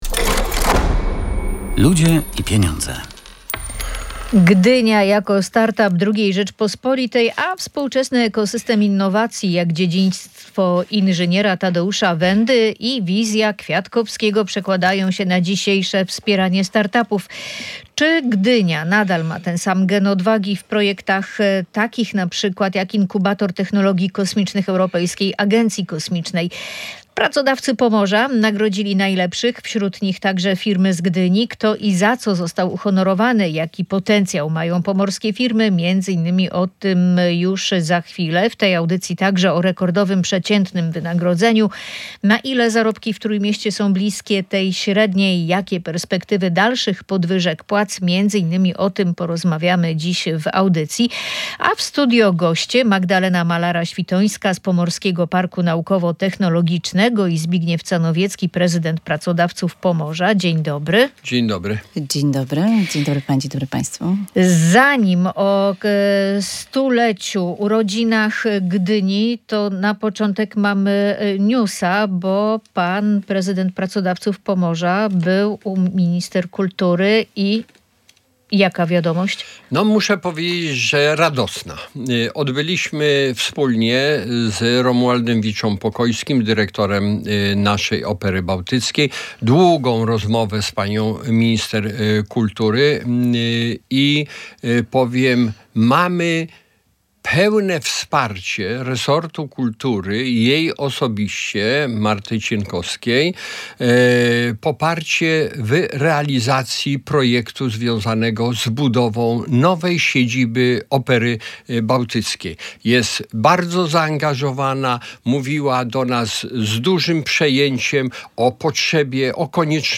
Gdynia była bohaterką audycji „Ludzie i Pieniądze”.